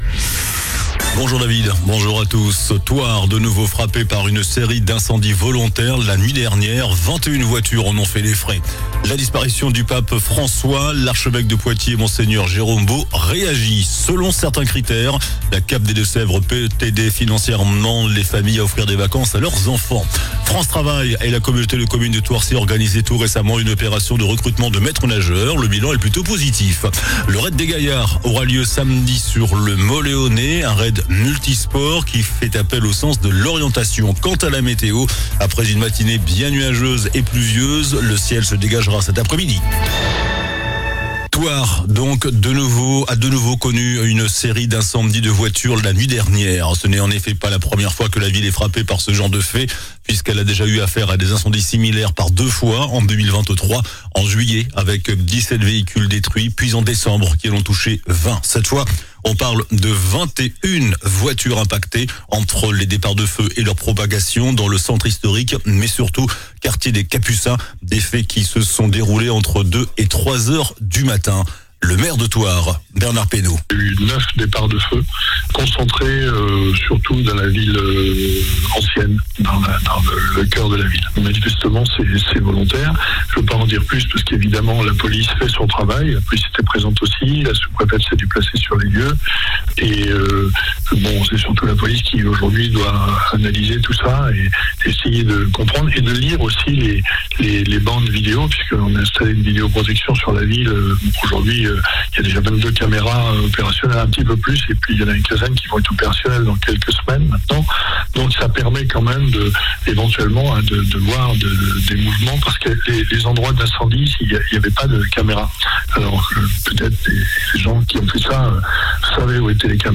JOURNAL DU MERCREDI 23 AVRIL ( MIDI )